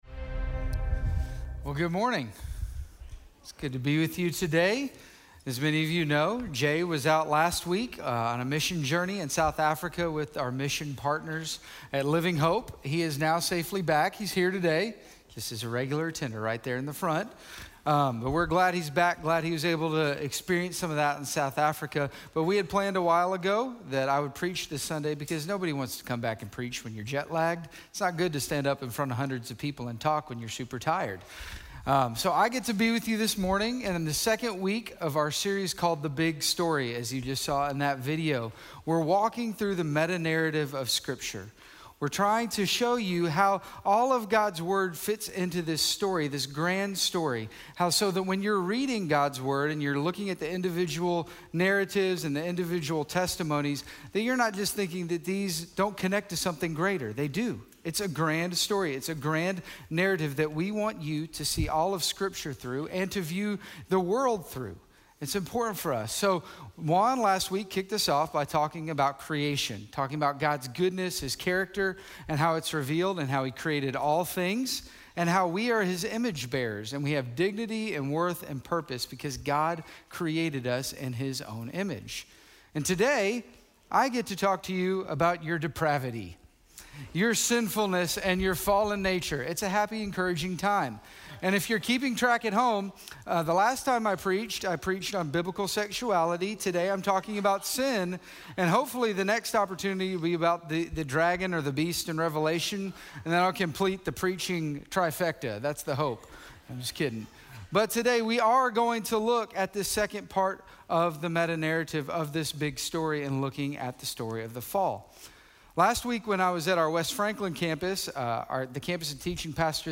The Story of Separation - Sermon - Station Hill